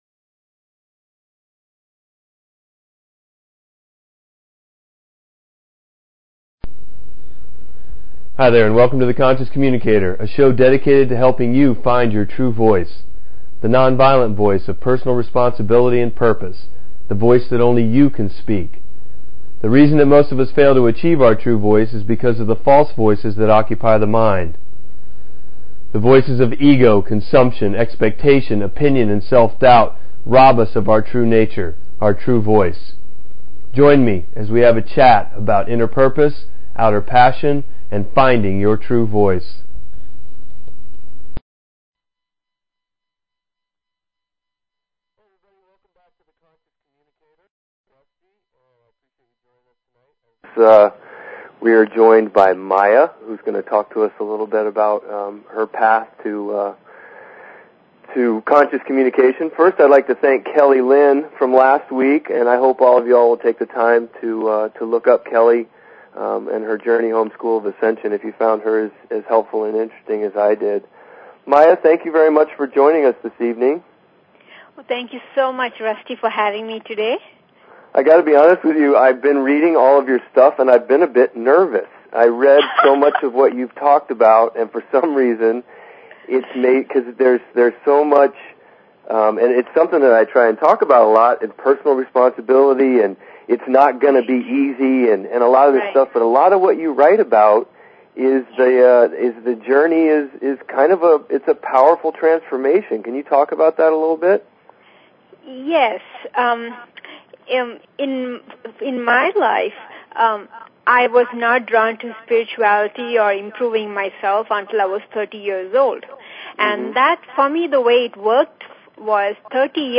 Talk Show Episode, Audio Podcast, The_Conscious_Communicator and Courtesy of BBS Radio on , show guests , about , categorized as